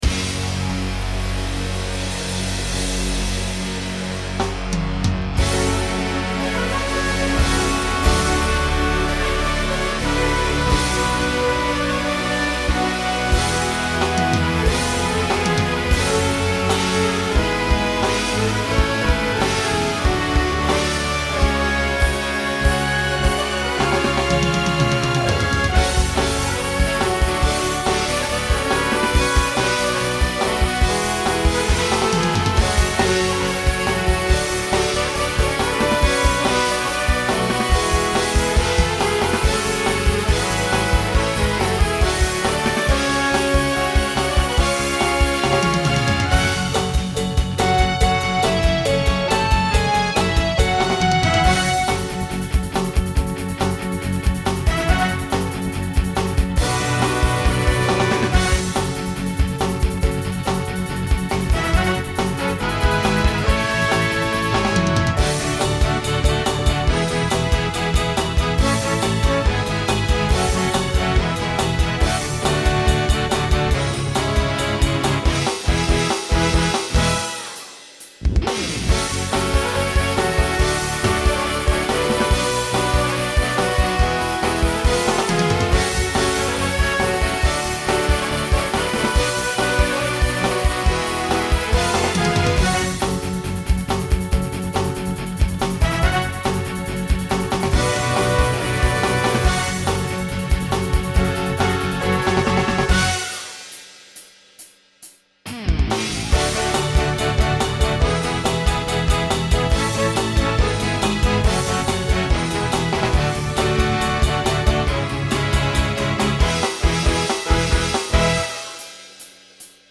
New TTB voicing for 2025.